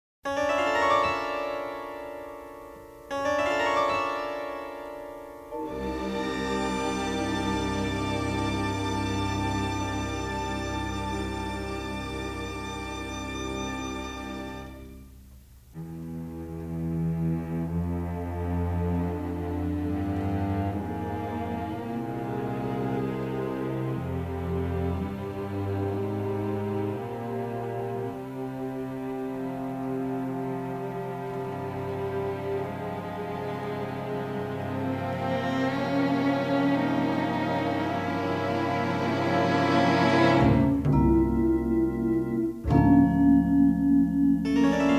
and a creepy score including harpsichord and solo soprano.